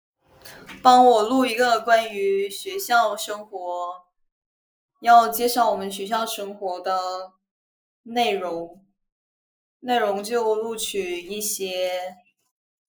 自然な学校VlogナレーションAI
テキスト読み上げ
親しみやすいトーン
Vlogナレーション